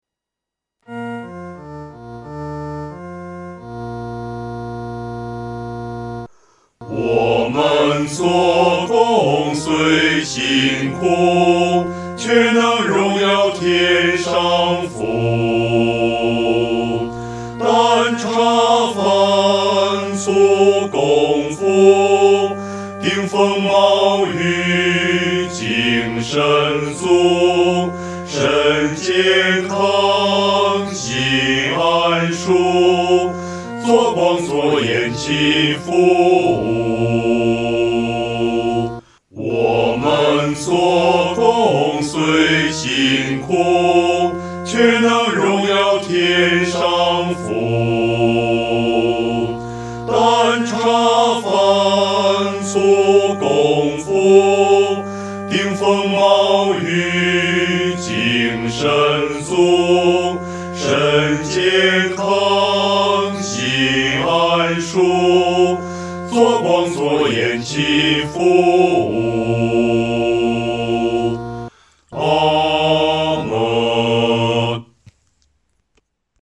男低
曲调很朴素，用的是五声音阶，农村信徒很容易上口。